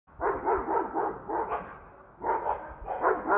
Dogs Barking Neighborhood Sounds Sound Effect Download: Instant Soundboard Button